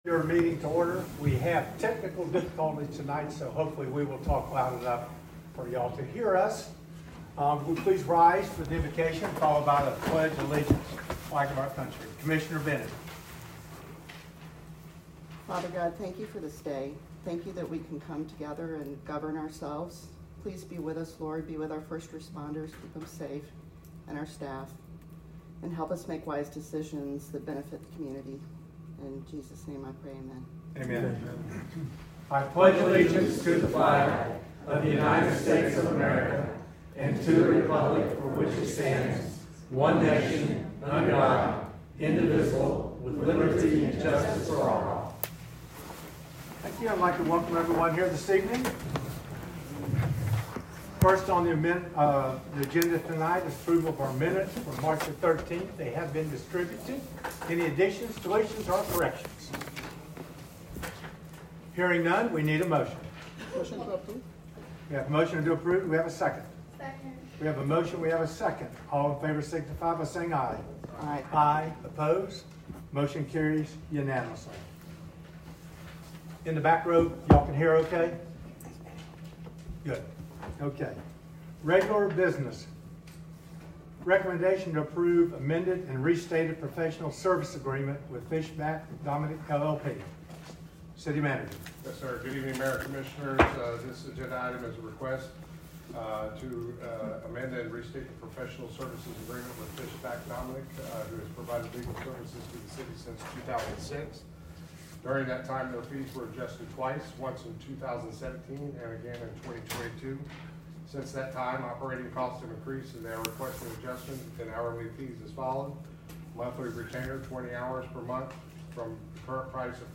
March 27, 2025, Regular Meeting.